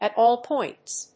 アクセントat áll póints